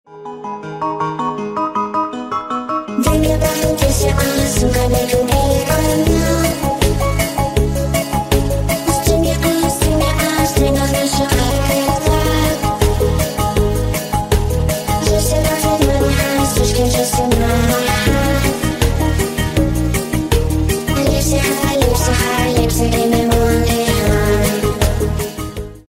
Весёлые Рингтоны
Рингтоны Ремиксы